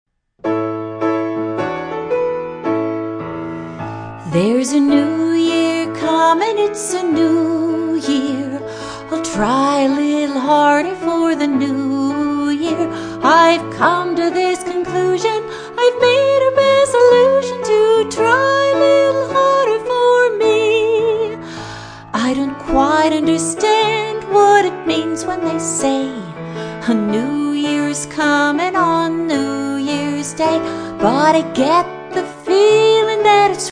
New Year's Day song for children
▪ Vocal track in MP3 format